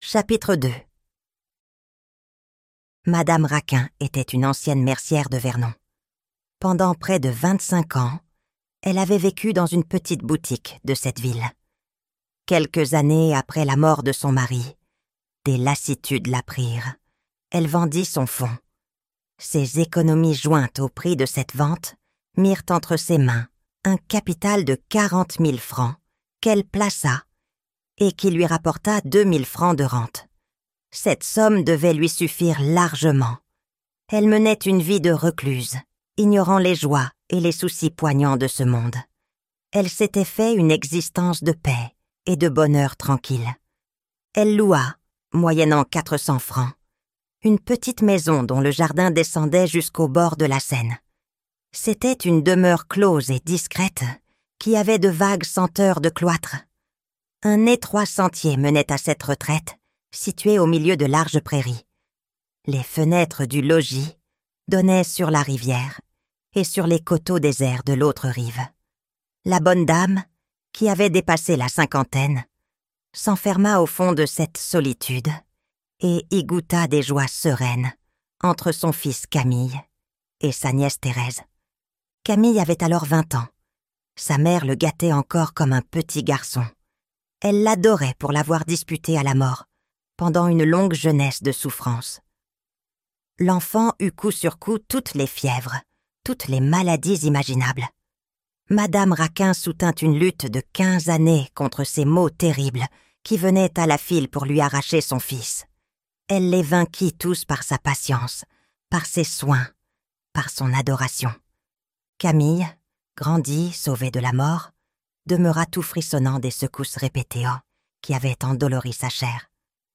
Thérèse Raquin - Livre Audio